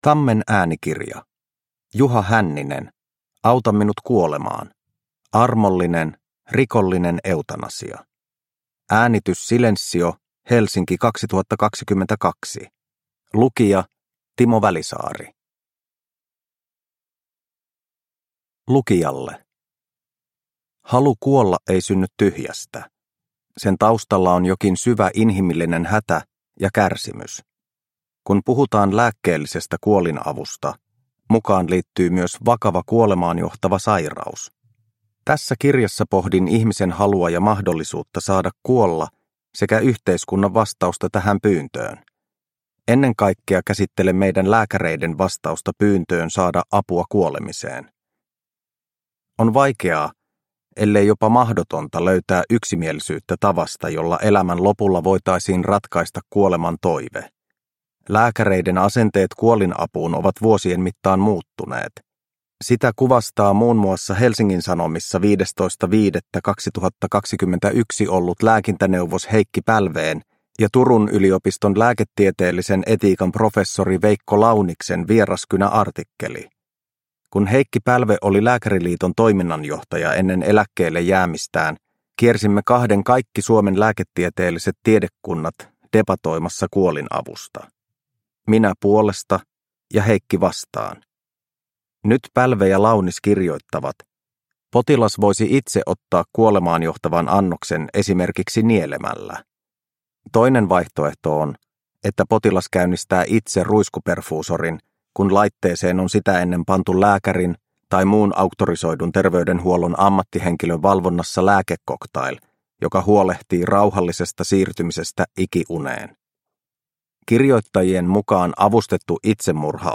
Auta minut kuolemaan – Ljudbok – Laddas ner